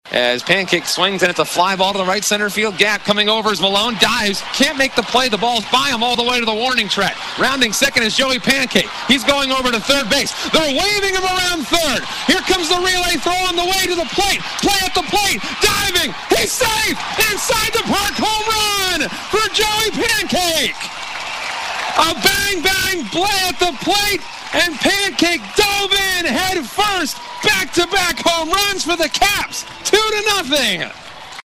Radio call